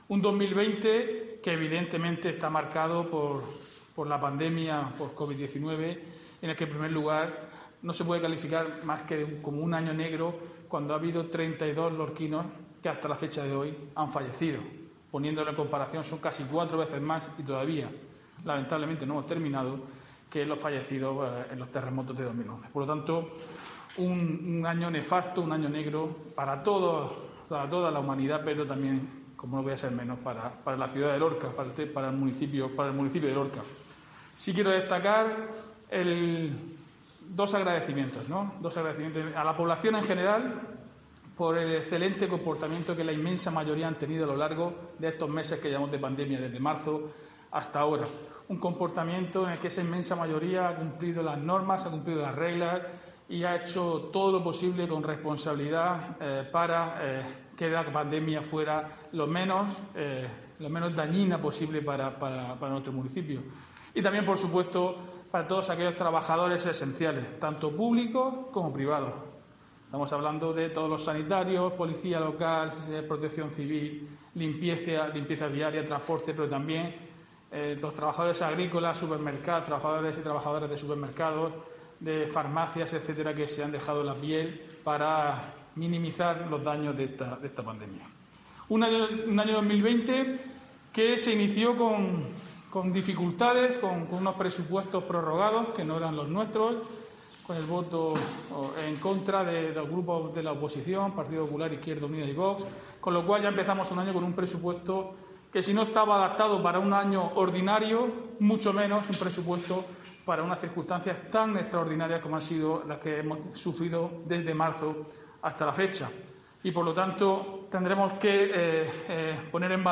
Diego José Mateos, alcalde de Lorca sobre el balance de 2020